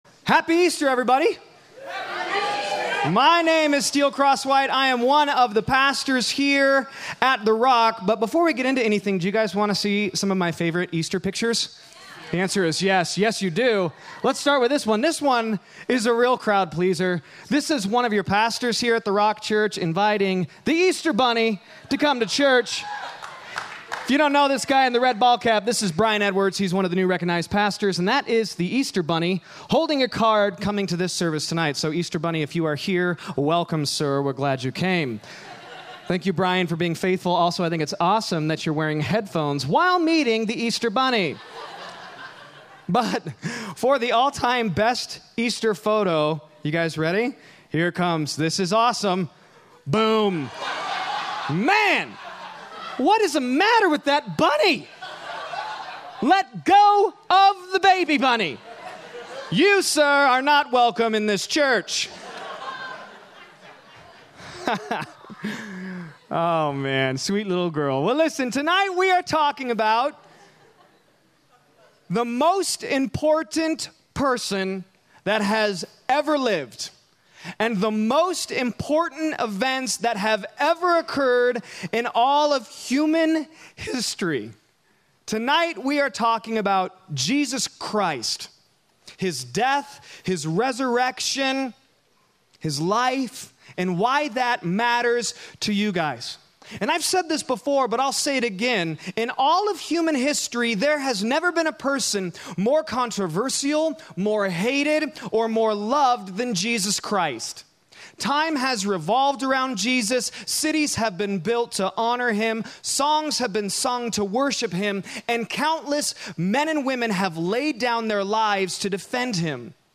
A message from the series "Death to Life."